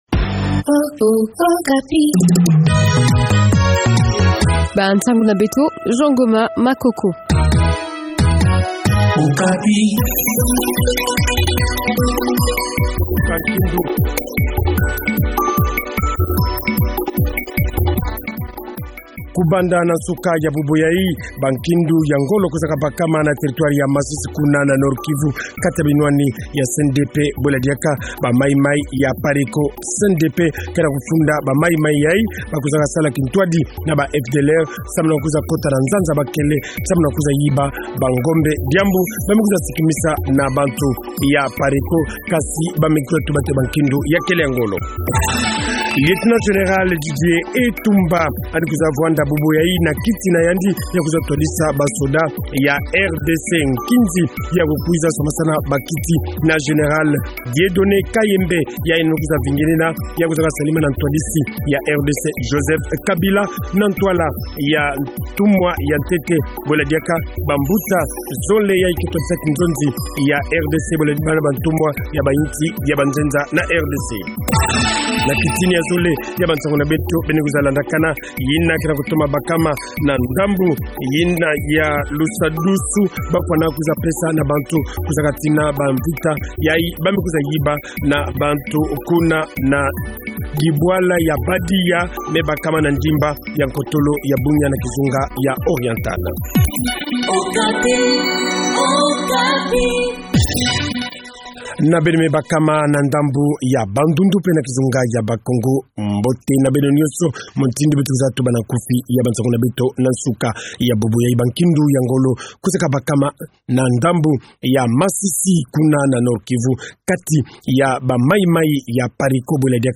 Journal Kikongo Soir